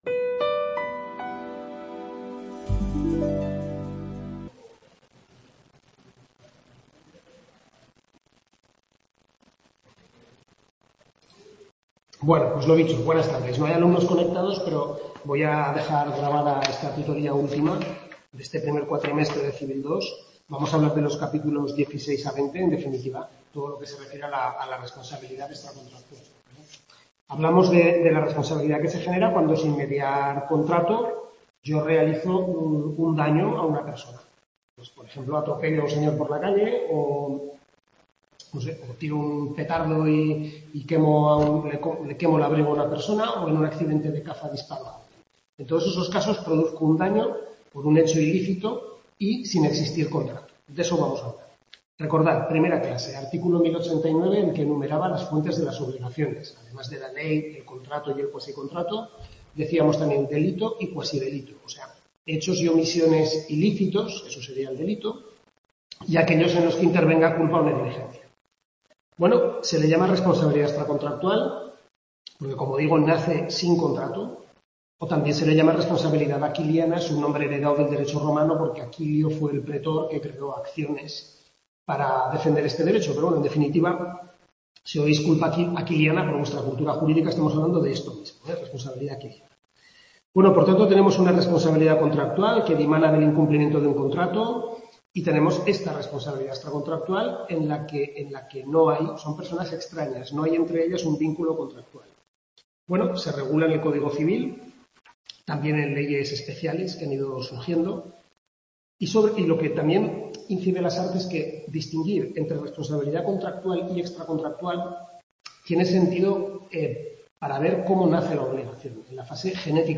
Tutoría 6/6 primer cuatrimestre de Civil II (Obligaciones), centro UNED Calatayud, capítulos 16-20 del Manual del Profesor Lasarte